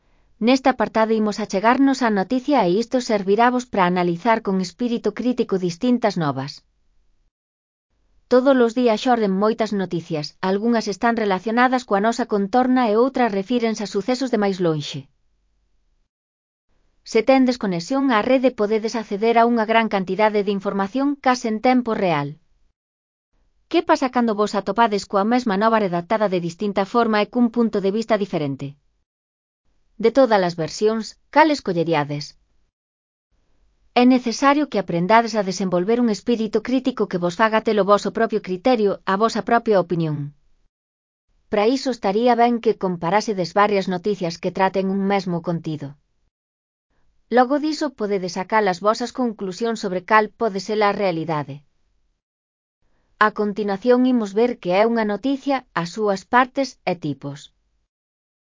Elaboración propia (Proxecto cREAgal) con apoio de IA, voz sintética xerada co modelo Celtia. A noticia (CC BY-NC-SA)